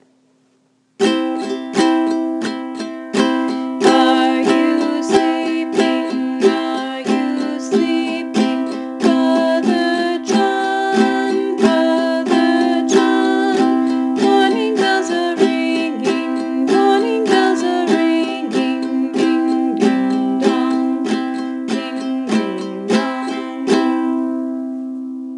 Then you use your right hand to strum all four strings just over the sound hole.
Just one chord.